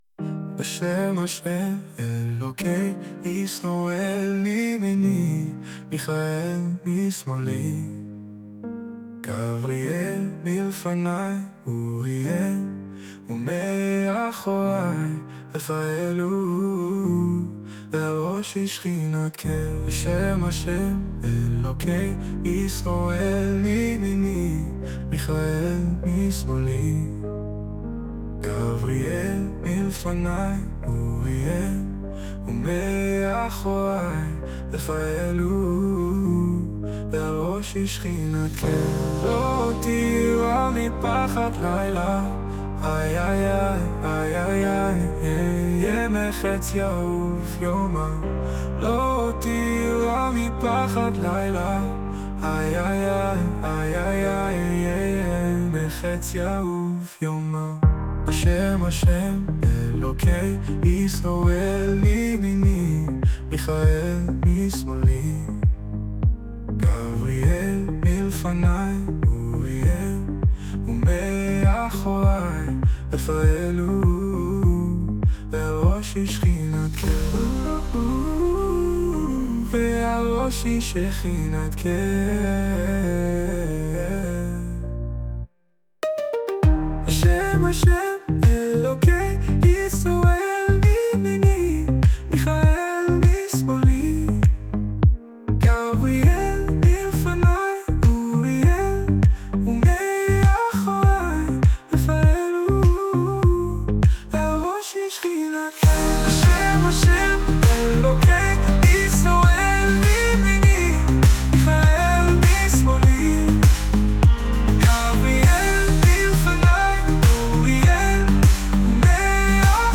Audio - שיתוף שירים שנוצרו ב- AI
גרסה נוספת לשיר פורים מקפיץ, שובב (מאוד) וגם מצחיק.